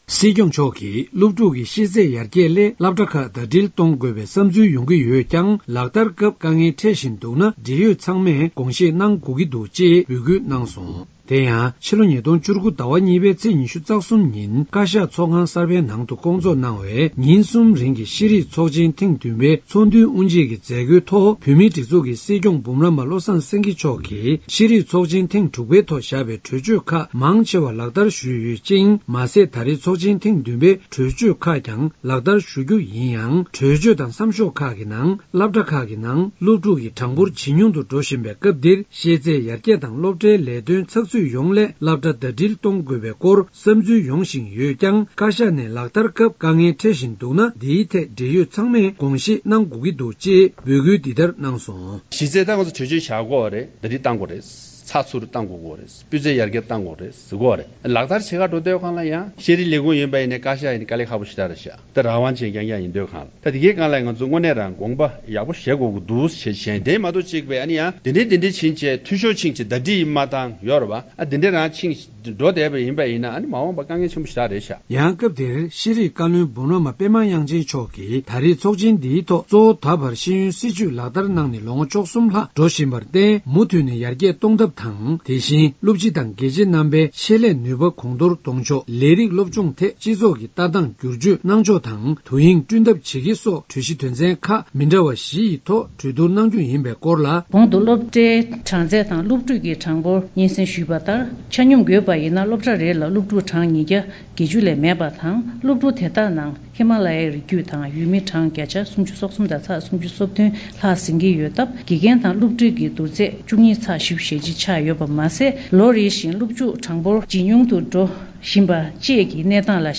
བཞུགས་སྒར་དུ་ཤེས་རིག་ཚོགས་ཆེན་ཐེངས་བདུན་པ་དབུ་འཛུགས་གནང་ཡོད་པ་དང་དབུ་འབྱེད་མཛད་སྒོའི་ཐོག་སྲིད་སྐྱོང་མཆོག་ནས་གསུང་བཤད་གནང་ཡོད་པ།